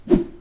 Звук галочки взмах